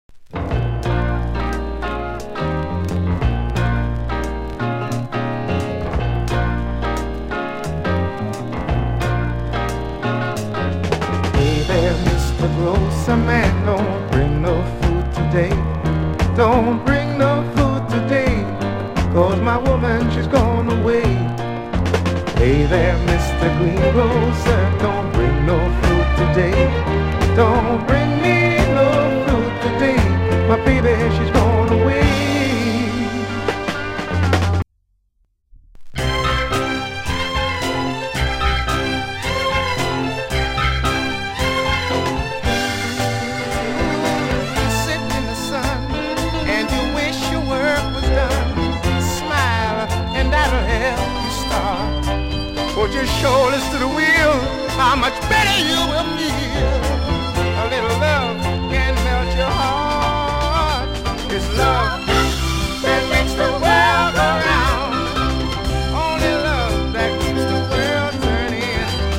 (税込￥2750)   UK SOUL